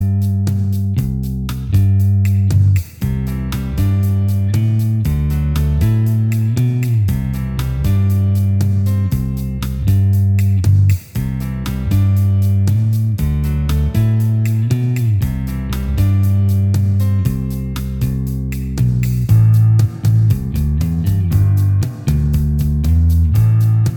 Minus Guitars Indie / Alternative 5:15 Buy £1.50